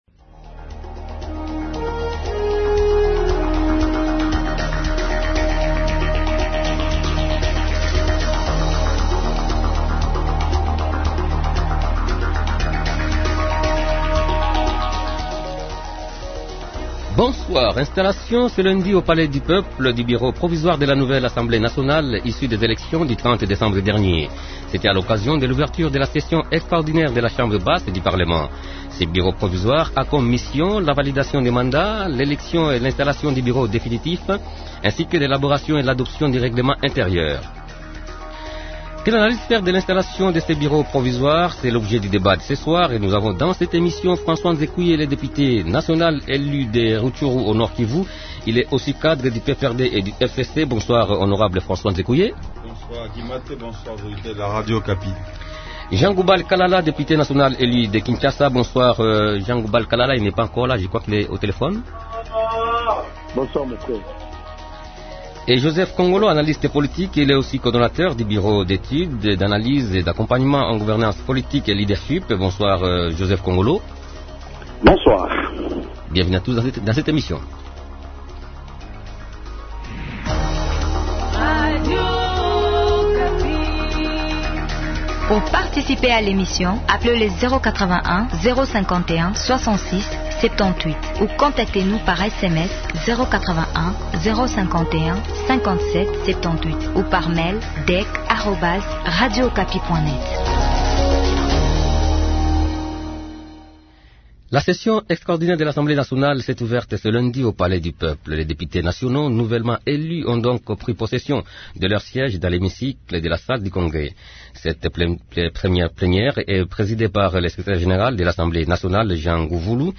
Invités : -François Nzekuye, Député national élu de Rutshuru au Nord-Kivu.
-Jean Goubald Kalala, Député national élu de Kinshasa.